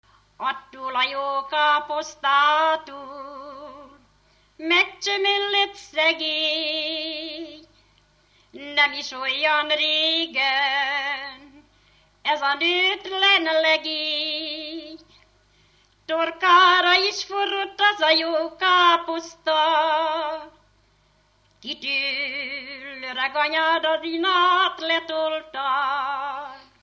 Alföld - Pest-Pilis-Solt-Kiskun vm. - Zagyvarékas
ének
Dallamtípus: Lóbúcsúztató - halottas 1
Stílus: 8. Újszerű kisambitusú dallamok
Kadencia: 2 (2) X 1